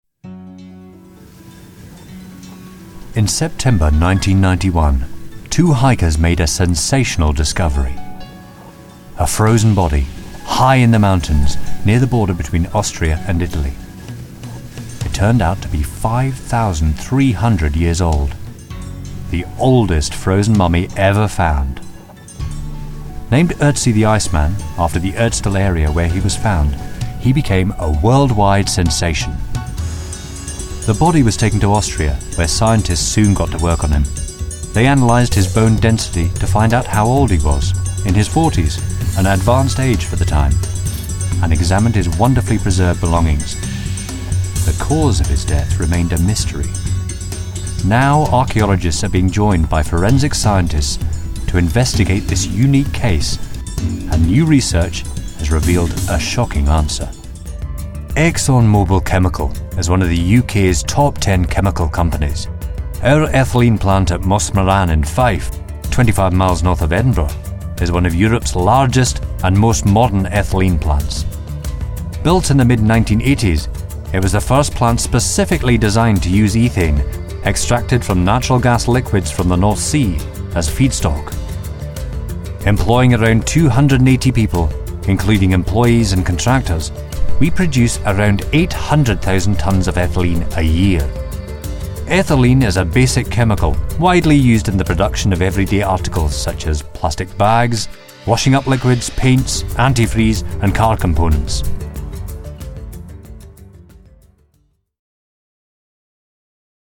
Narration Showreel
Male
Neutral British
British RP
Informative
Relaxed